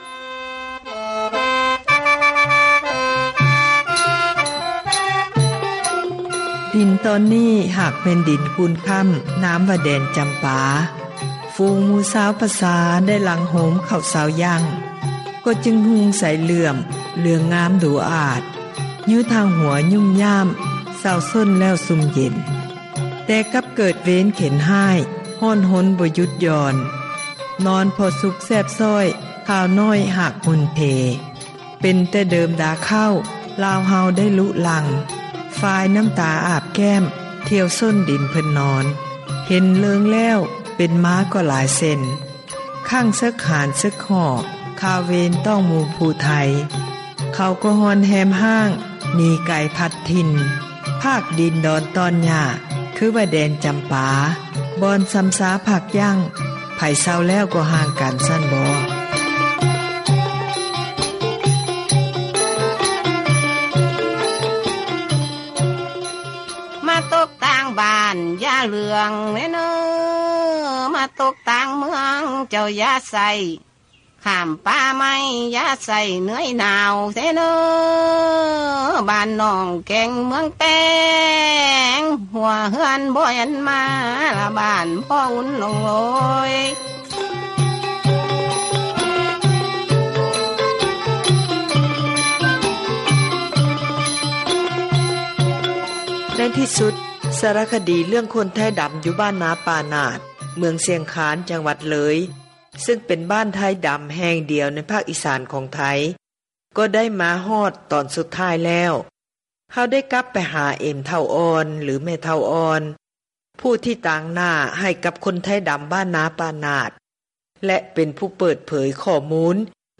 ສາຣະຄະດີ ໄທດໍາ ຢູ່ ເມືອງຊຽງຄານ